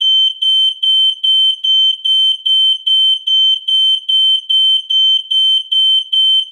Loud Fire Alarm Sound Effect Free Download
Loud Fire Alarm